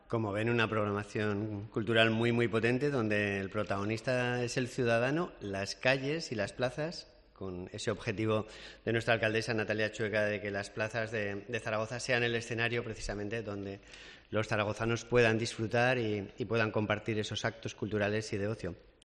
El consejero de Presidencia, Ángel Lorén, valora los actos organizados para este día de San Valero